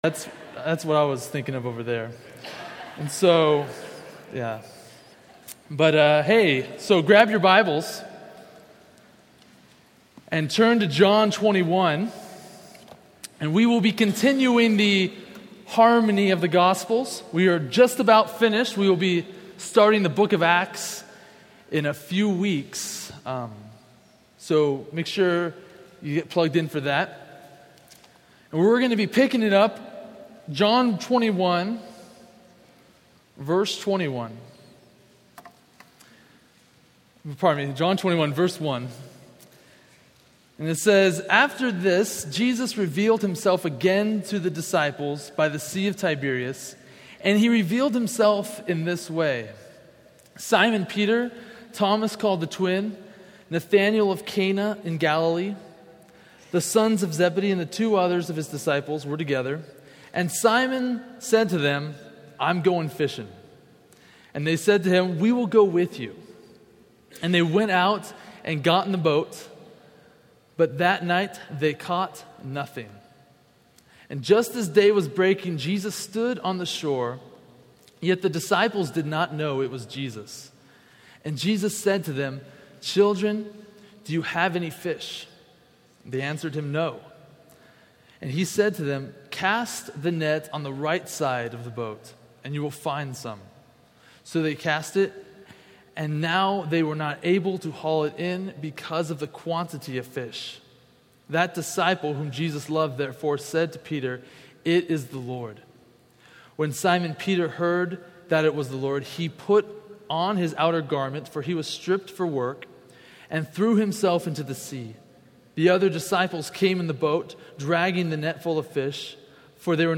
A message from the series "(Untitled Series)." Scripture: John 21:1–21:14